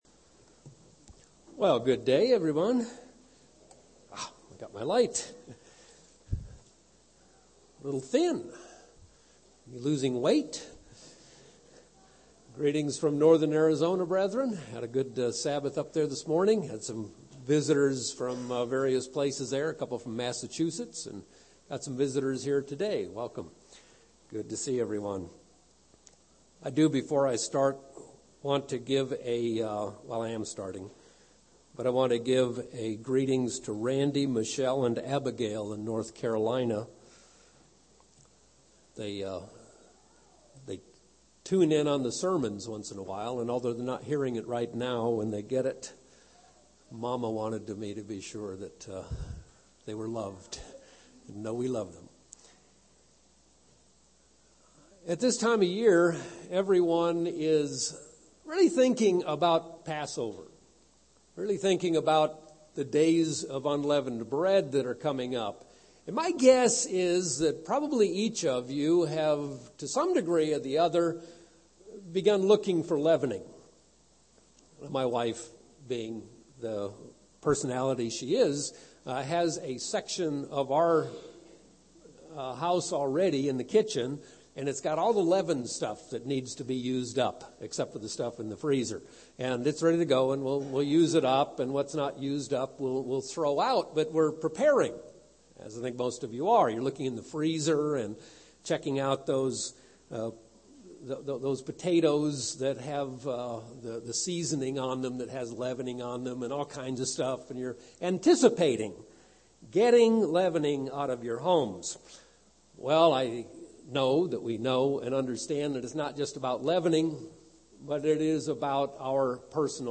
Given in Phoenix East, AZ
UCG Sermon Studying the bible?